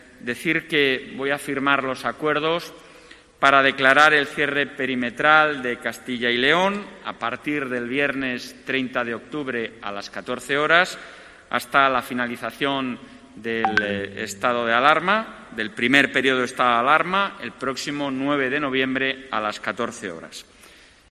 declaración Institucional de los 3 presidentes